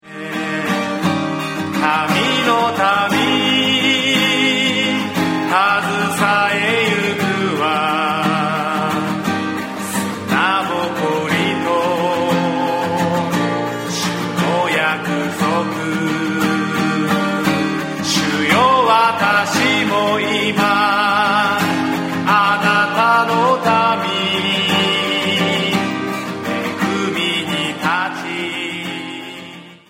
• ブラジルの賛美歌